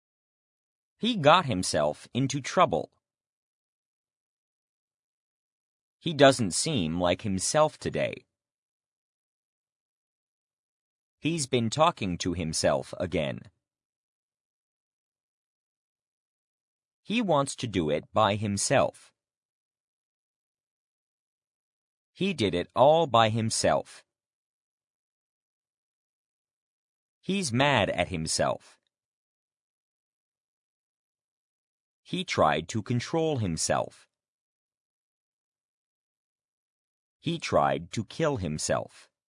himself (pro): him and no one else < previous > next Listen to the Lesson | Listen with pause Example sentences: " He got himself into trouble.